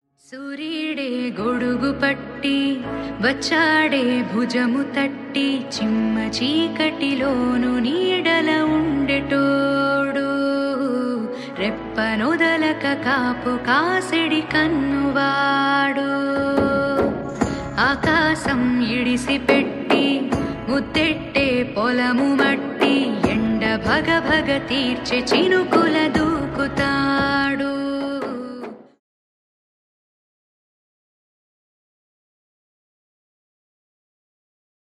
BGM ringtone